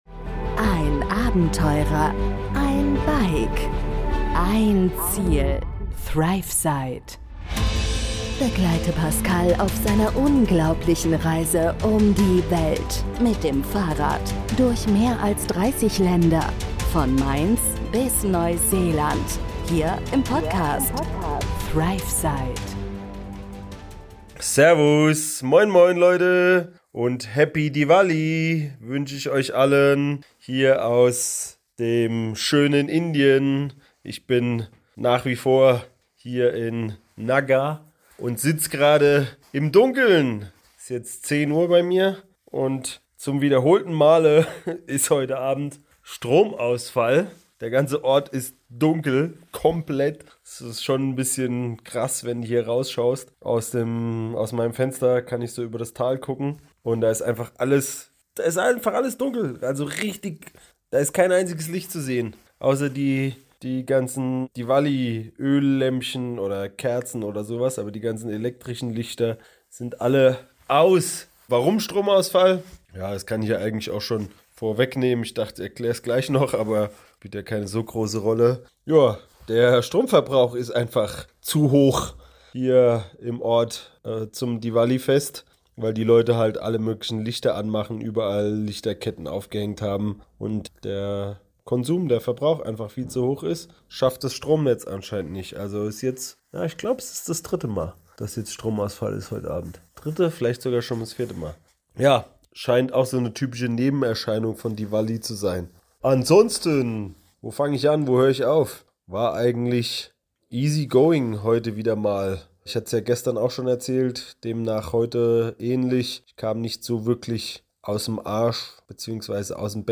Himalaya-Abenteuer & Diwali-Erlebnisse im Dunkeln  In der neuen Folge melde ich mich live aus dem indischen Himalaya – direkt aus Naga, wo der Strom während Diwali mehrmals ausgefallen ist.